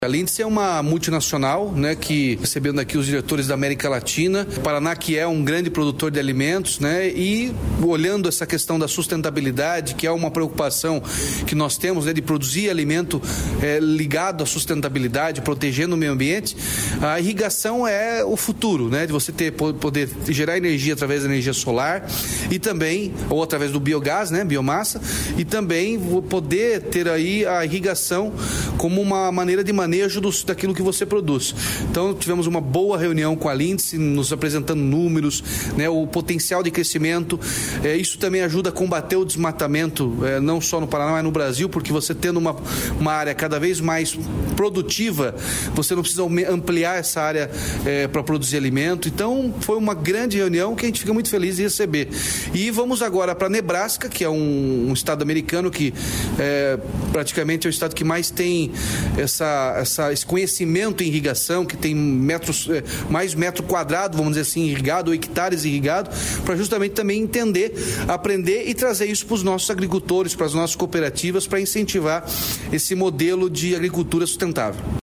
Sonora do governador Ratinho Junior sobre potencialidade no sistema de irrigação